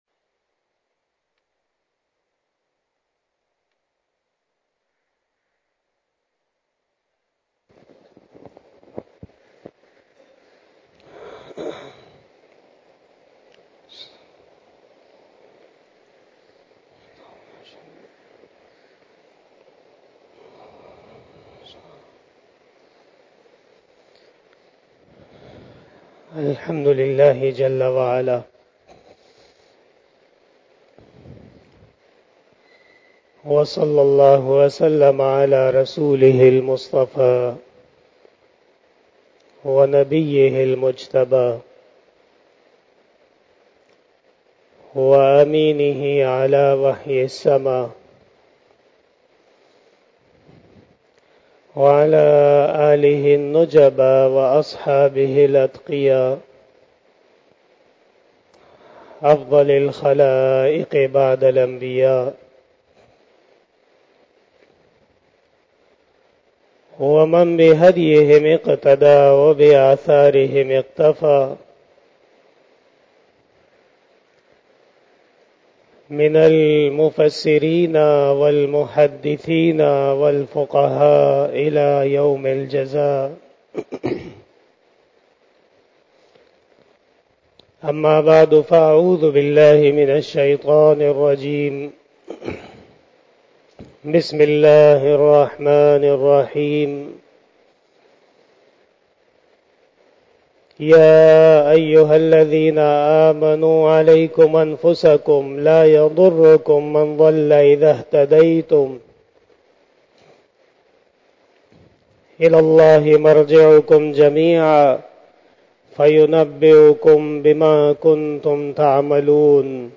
Bayan-e-Jummah-tul-Mubarak